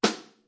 VSCO 1打击乐库 鼓 " 小鼓（小鼓1 rimshot fff 1
Tag: 边敲击 打击乐器 小鼓 VSCO-2 单票据 多重采样